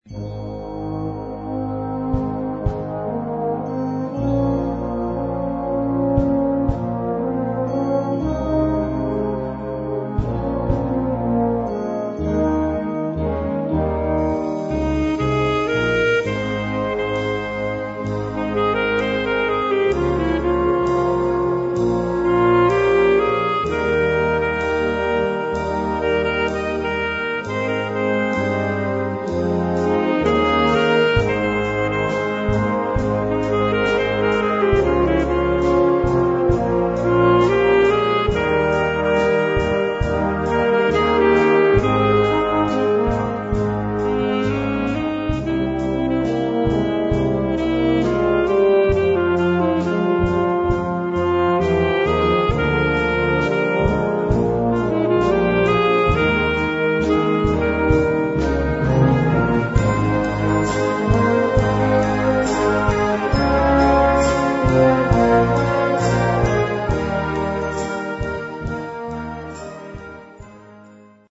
Gattung: Solo für Trompete oder Altsaxophon
Besetzung: Blasorchester
Sehr gefühlvolle Ballade für Trompete oder Altsaxophon.